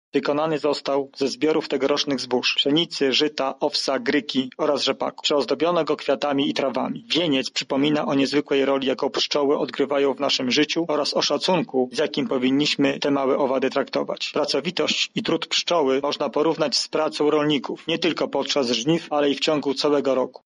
-mówi członek Zarządu Województwa Lubelskiego Sebastian Trojak